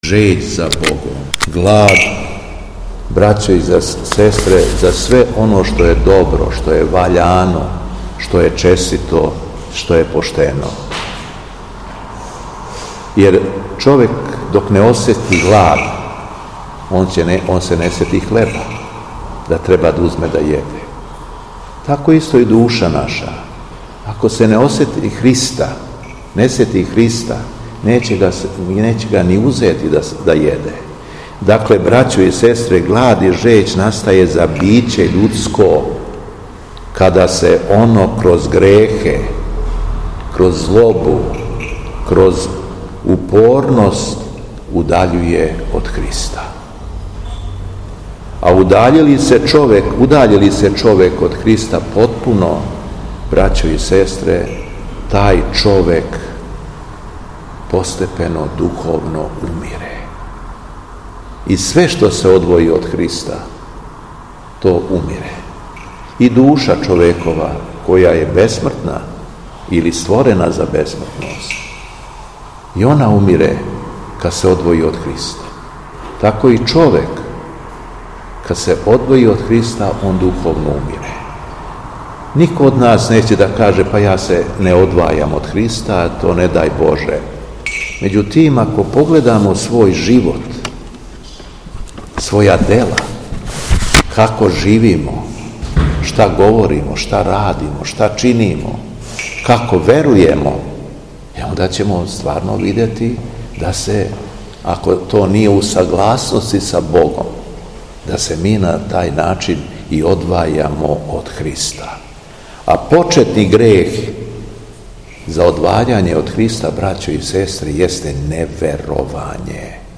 Беседа Његовог Високопреосвештенства Митрополита шумадијског г. Јована
После прочитаног јеванђелског зачала Високопреосвећени се обратио верном народу надахнутом беседом:
У среду трећу по Васкрсу, седмог маја, Његово Високопреосвештенство Митрополит шумадијски Господин Јован служио је свету архијерејску литургију у храму Светога великомученика кнеза Лазара у Белошевцу уз саслужење братсва храма.